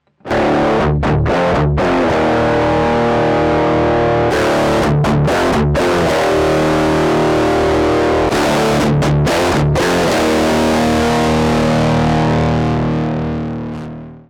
SubDecay Blackstar Klangbeispiele
Alle Klangbeispiele wurden mit einer ESP Eclipse II mit aktiven Humbucker in Drop D aufgenommen.
Mit Ausnahme des ersten Klangbeispiels wurden alle Aufnahmen direkt in eine DI-Box gemacht, damit Du sie in deinen eigenen Amp schicken kannst (Stichwort: Reamping Tutorial).